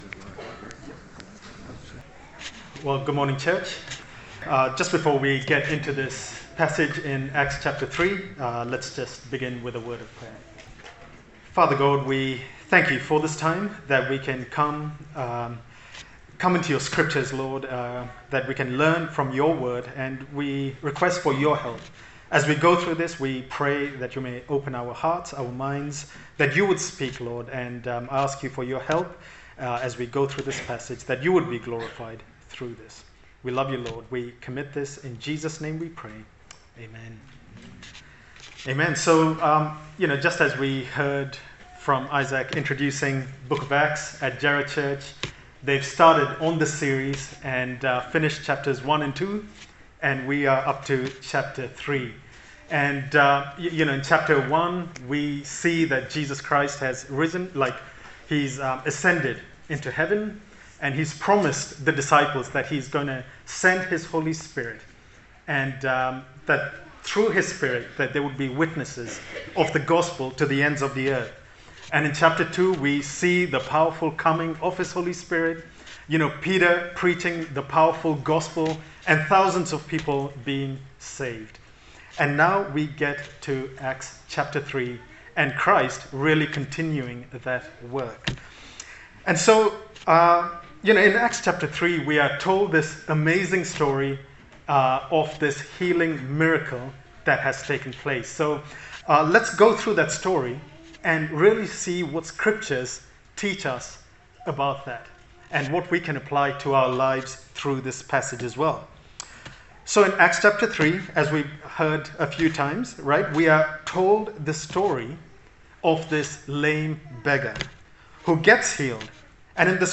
Acts Passage: Acts 3:1-10 Service Type: Sunday Service